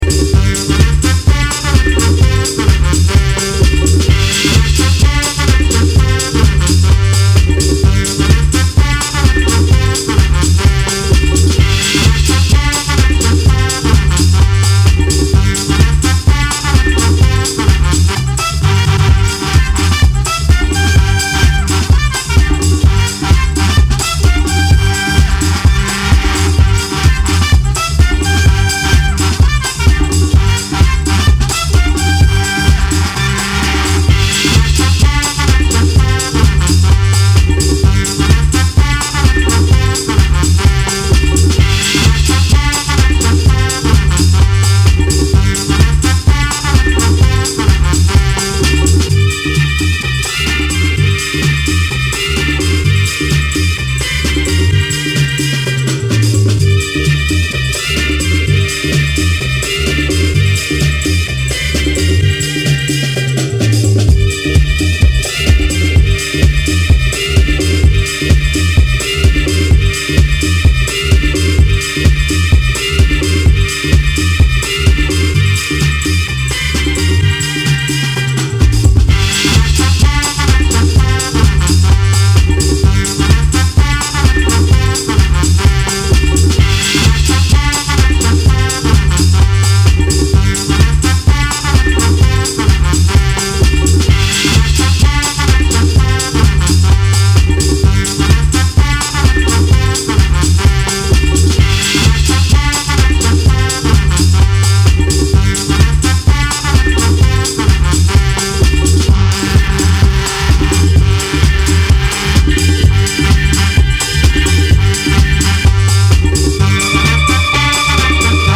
hot disco joints